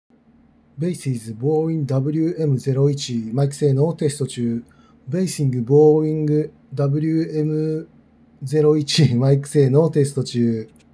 マイク性能はけっこういい
Baseus Bowie WM01のマイク性能
マイク性能はしっかりしている。1万円以下では平均よりちょい上ぐらいの性能です。